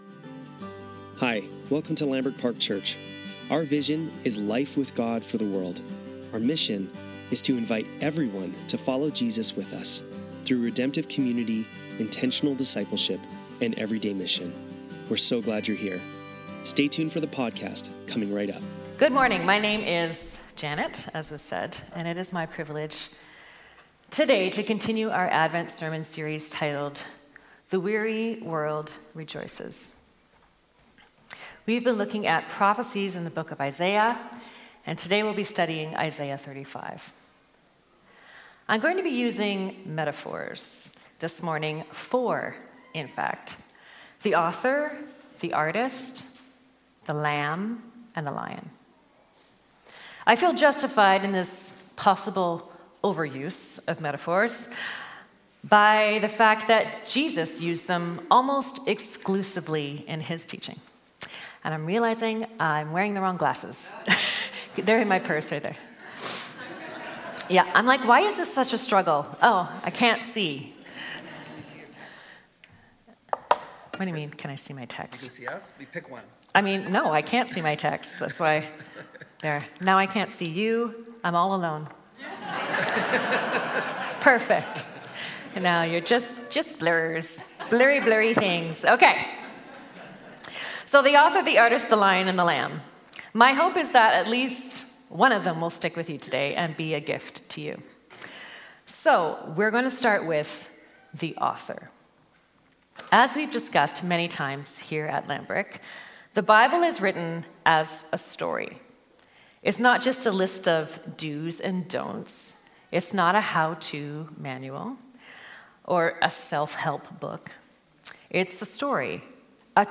Lambrick Sermons | Lambrick Park Church
Sunday Service - December 22, 2024